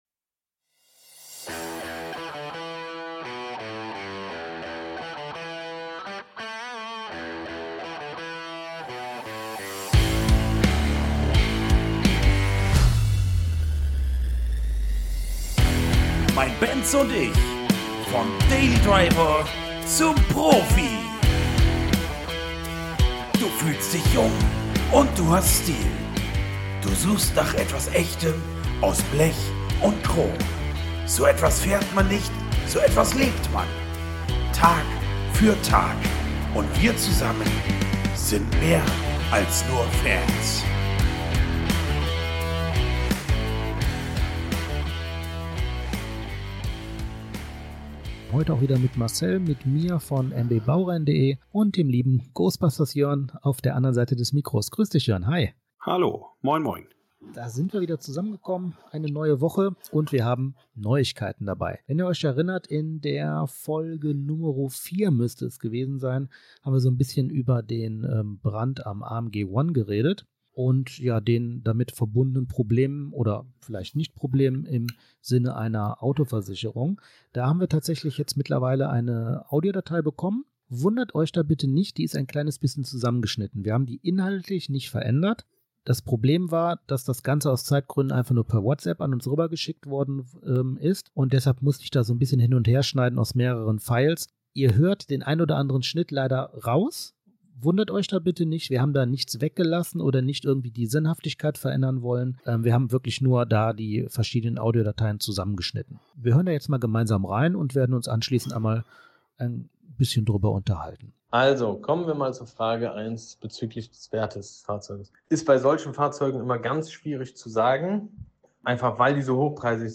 Heute geht es um alles was ihr noch nie über Kuchen & Autos wissen wolltet. Leider ist eine Tonspur kaputt und wir mussten das Beste (oder nichts) daraus machen. Die nächsten Folgen dann wieder mit der gewohnten Qualität.